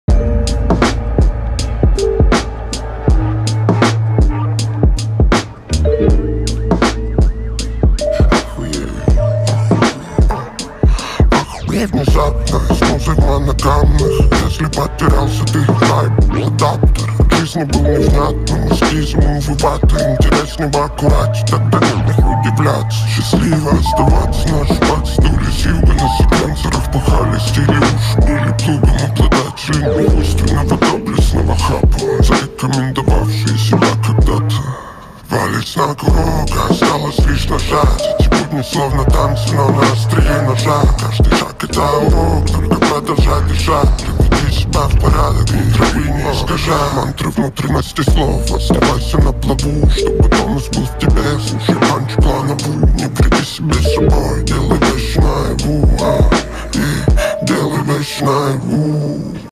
Рэп, Хип-хоп